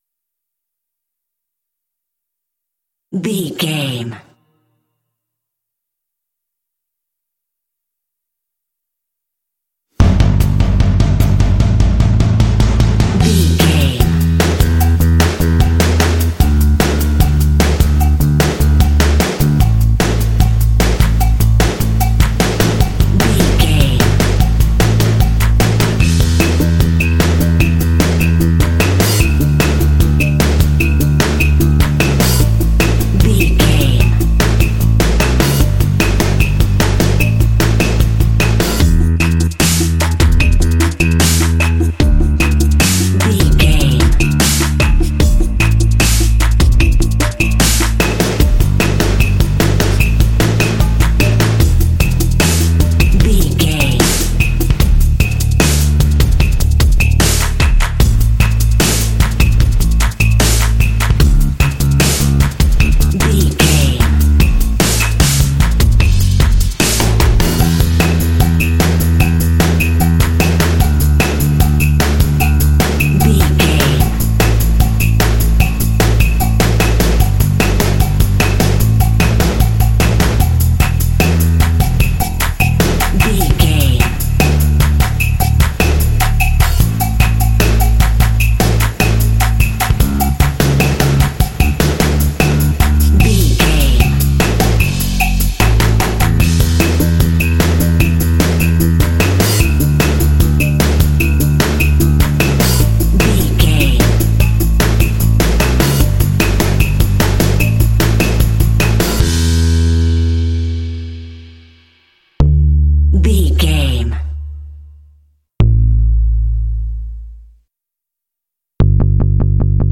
Uplifting
Ionian/Major
Fast
energetic
joyful
drums
percussion
bass guitar
playful
pop
contemporary underscore